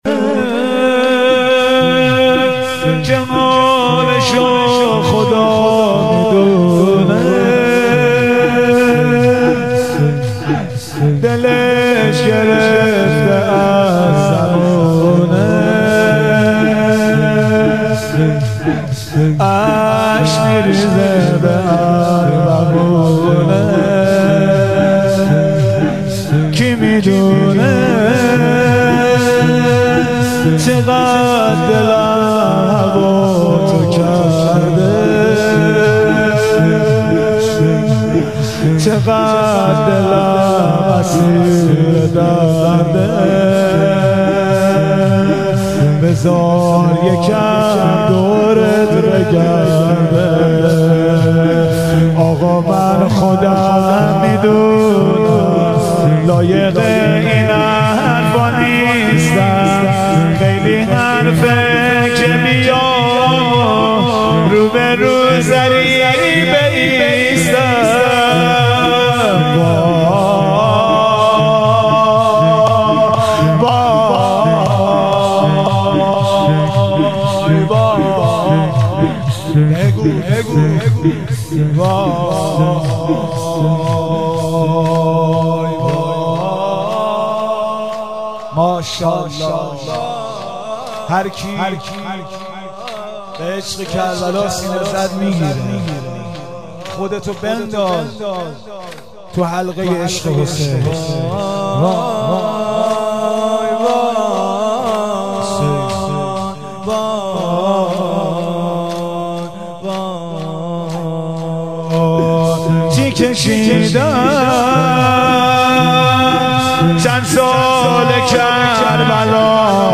مراسم هیئت هفتگی سپهسالار
مداحی